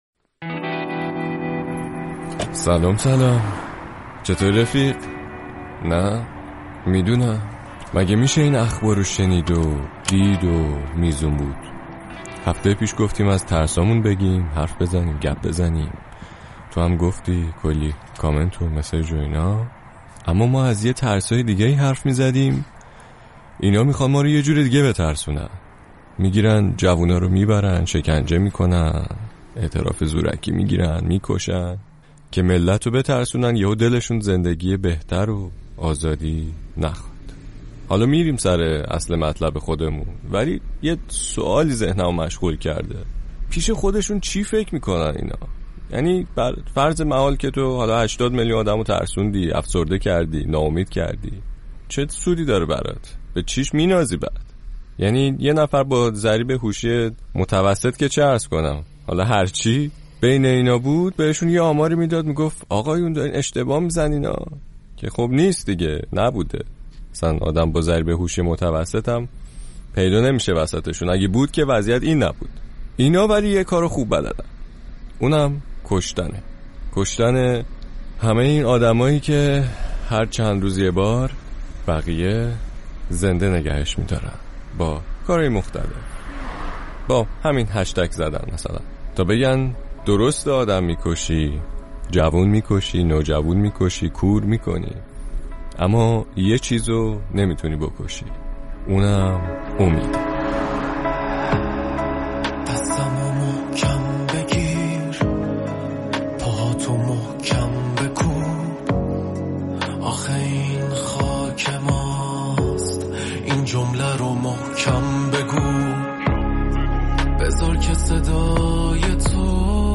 پادکست موسیقی